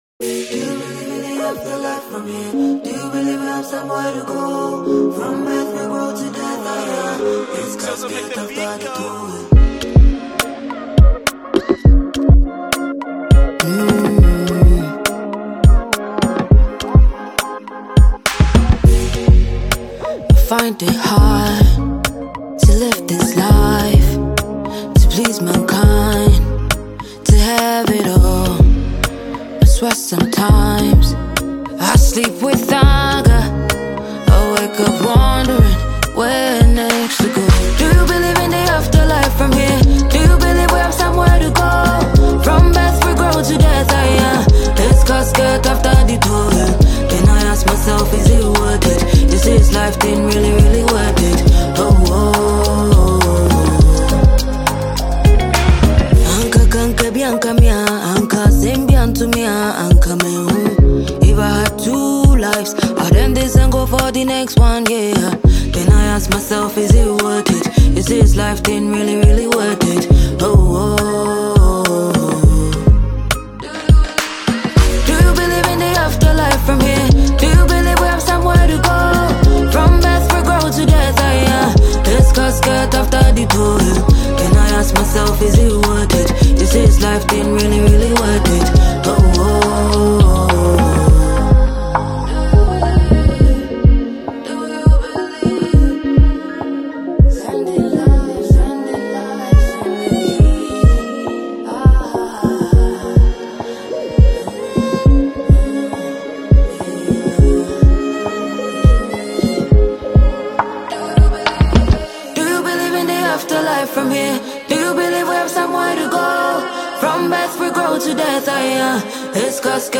vibrant and mood-evoking